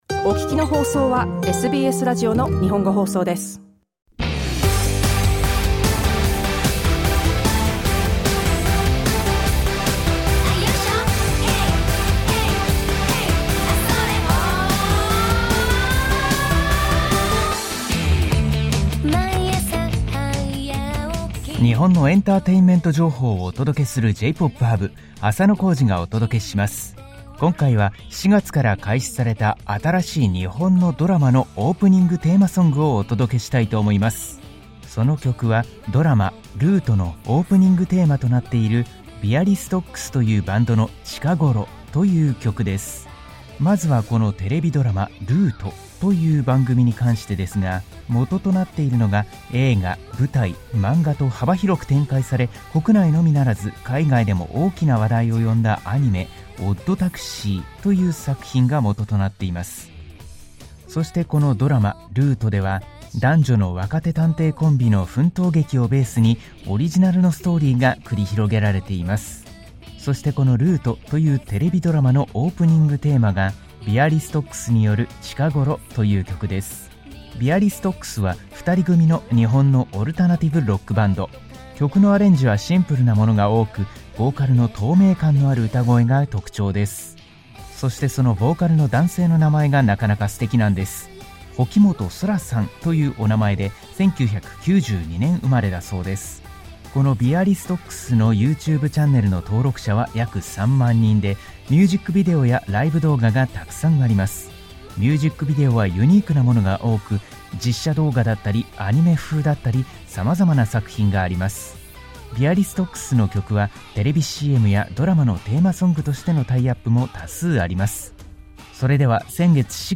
SBS Japanese's music segment J-Pop Hub is broadcast on Thursdays.